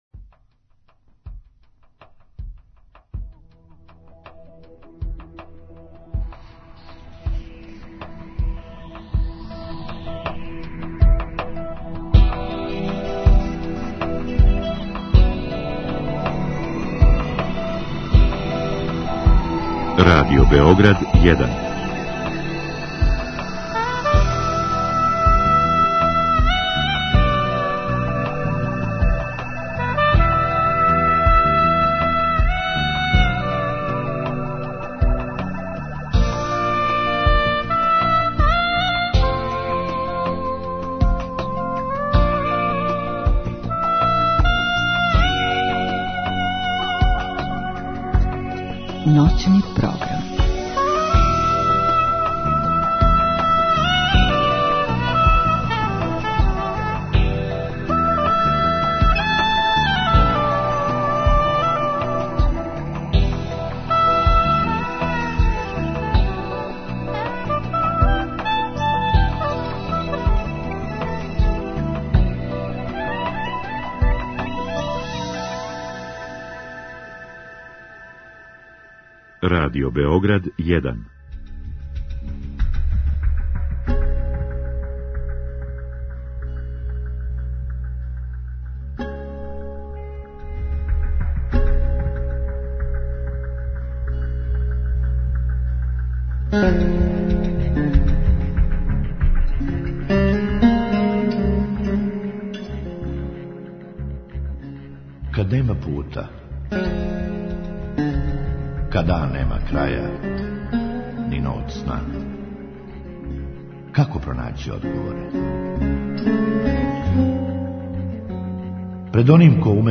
У другом сату отварамо телефонске линије за слушаоце, који у програму могу да поставе питања гошћи.